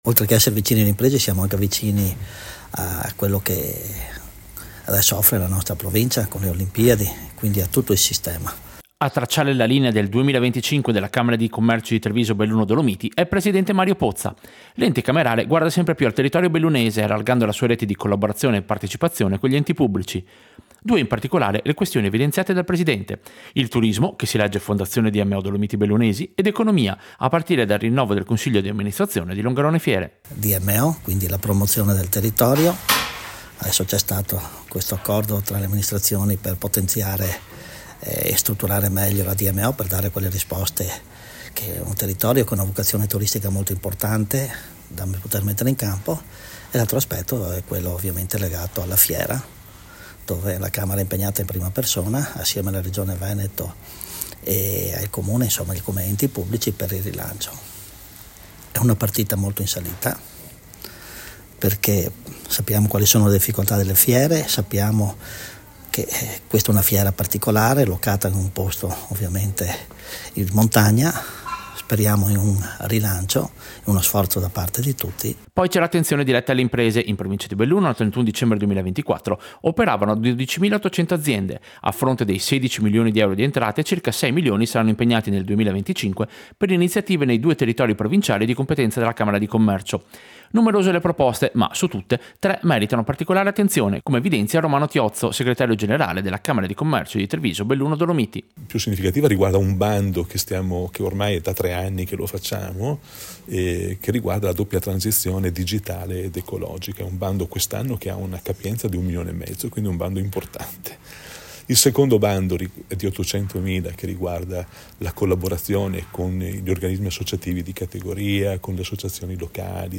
Servizio-Bilancio-e-iniziative-Camera-di-Commercio.mp3